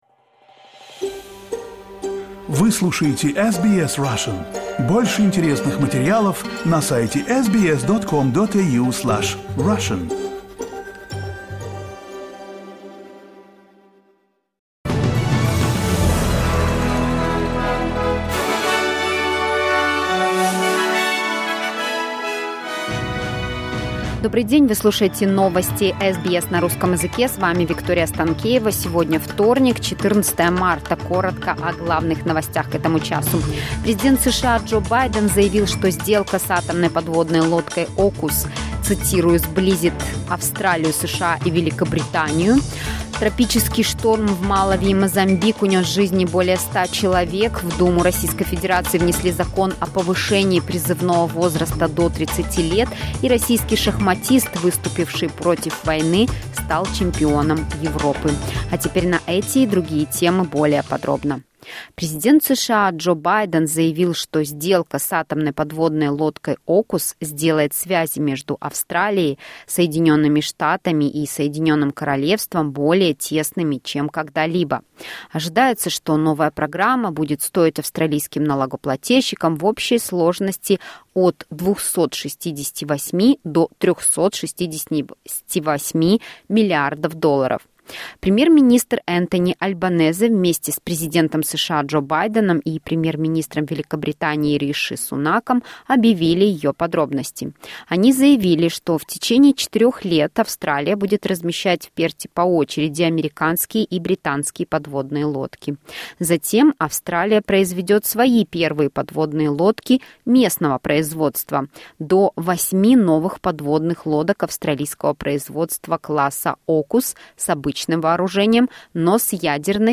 SBS news in Russian — 14.03.2023